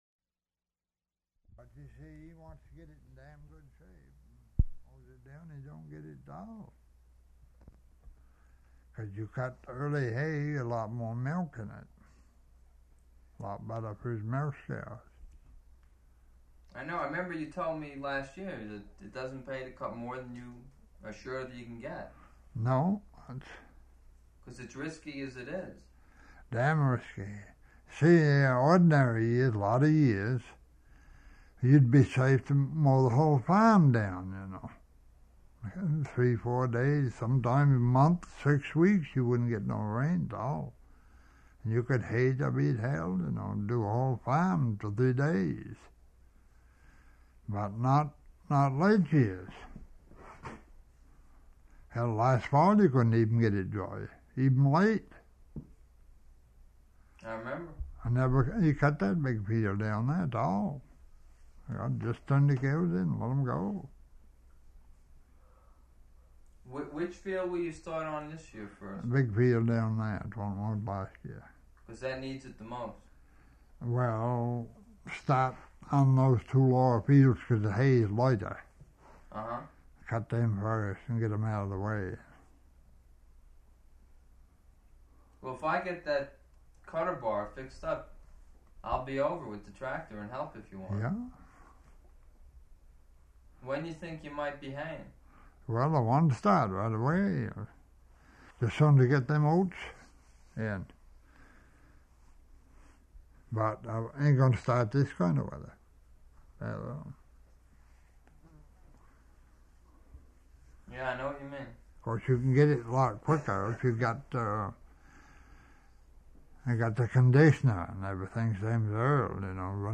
Interview
Format 1 sound tape reel (Scotch 3M 208 polyester) : analog ; 7 1/2 ips, full track, mono.